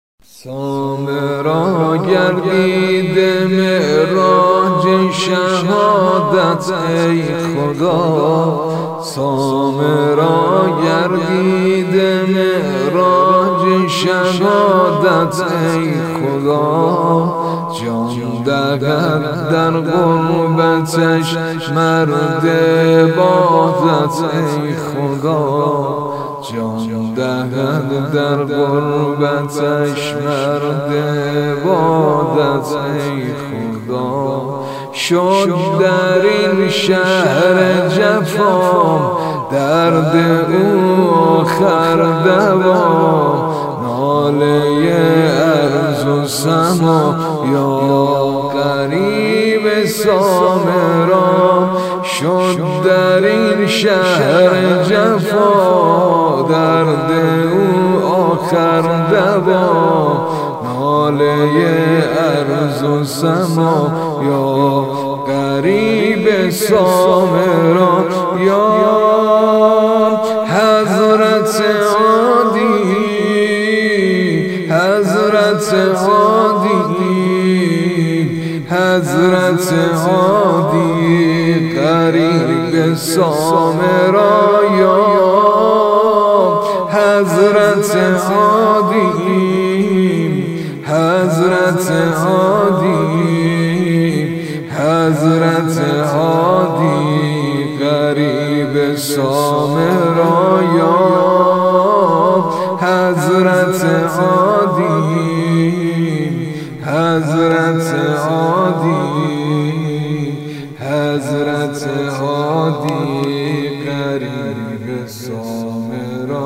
سبک ۱ ـ ذکر / زمینه سنگین